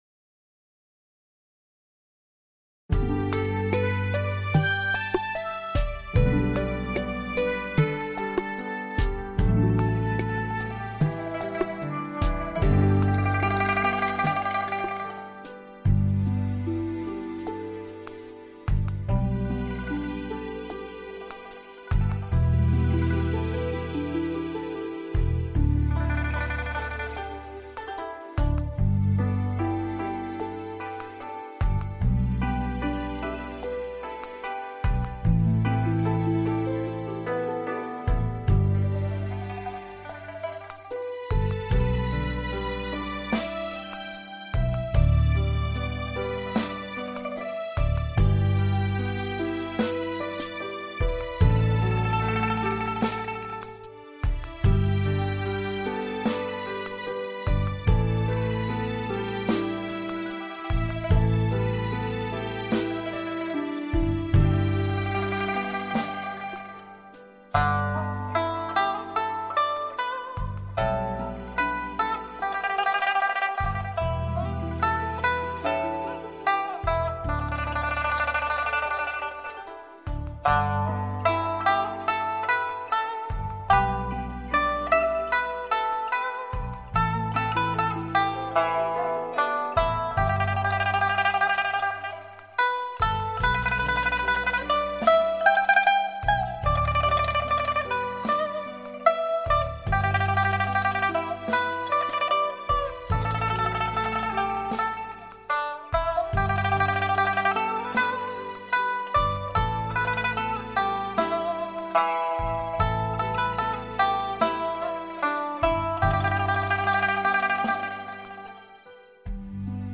G伴奏